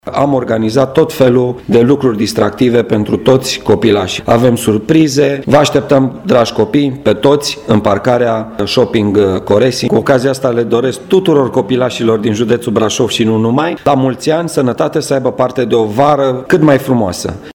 Prefectul Marian Rasaliu: